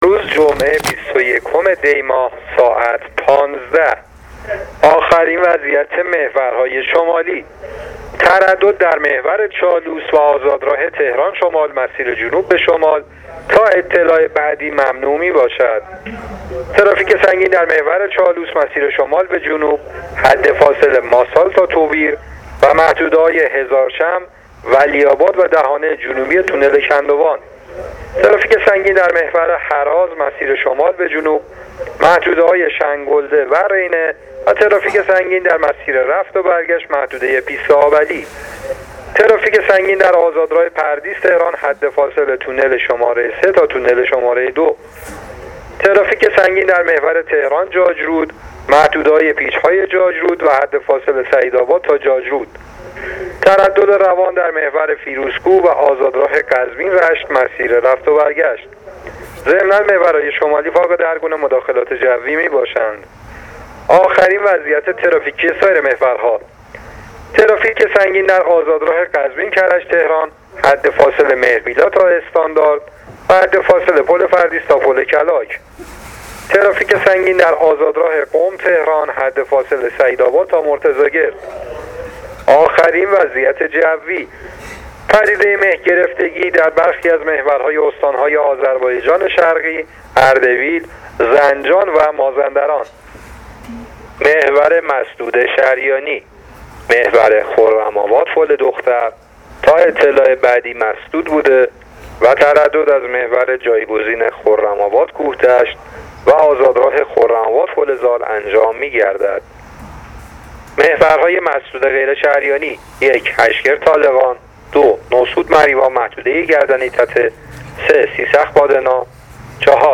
گزارش رادیو اینترنتی از آخرین وضعیت ترافیکی جاده‌ها تا ساعت ۱۵ بیست و یکم دی؛